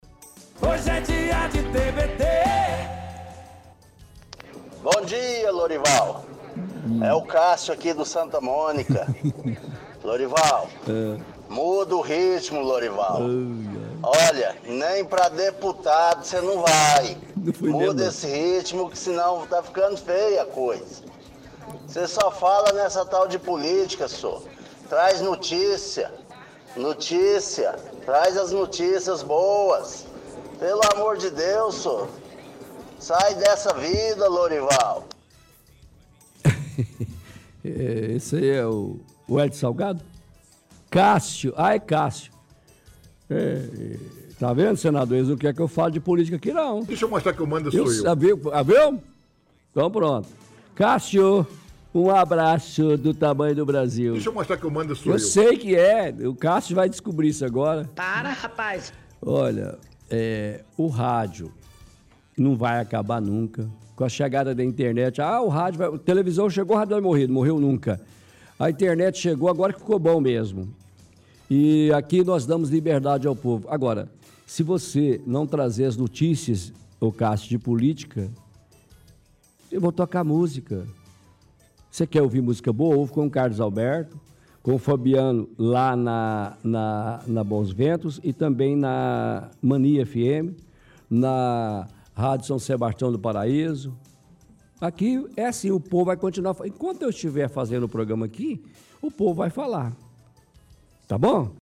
– Outro áudio antigo de ouvinte reclamando que rádio só fala mal da prefeitura e do prefeito.
– Áudio antigo de ouvinte dizendo que depois que a rádio começou a se envolver com política, perdeu muito a credibilidade.